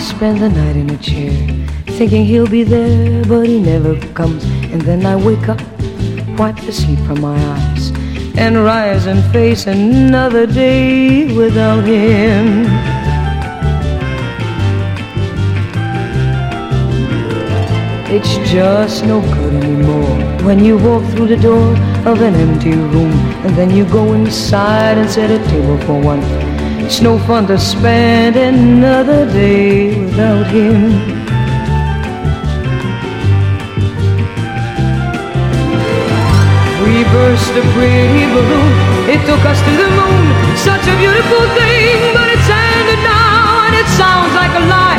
EASY LISTENING / VOCAL / SWING
ハッピーなショウビズ・スウィング！